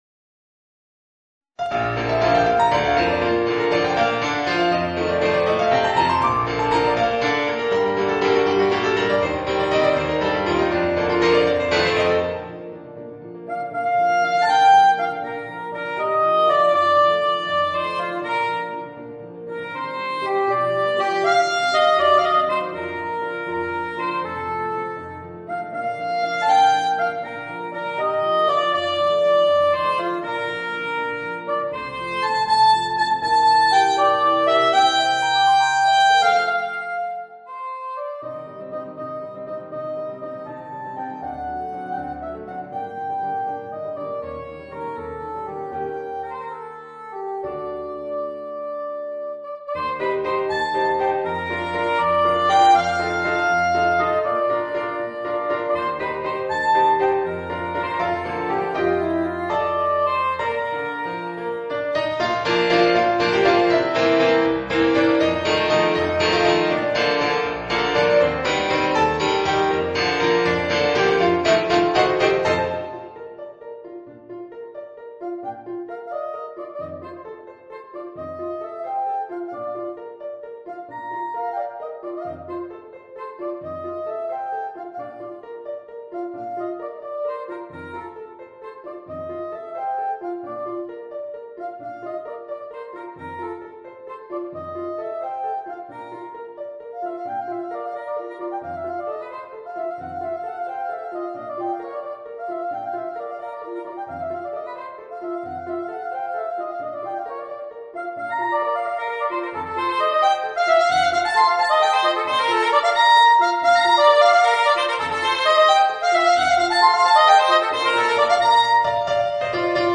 Voicing: Soprano Saxophone and Piano